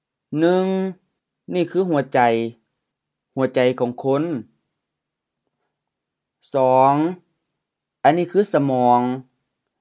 หัวใจ hu:a-jai M-M หัวใจ heart
สะหมอง sa-mɔ:ŋ M-M สมอง brain, brains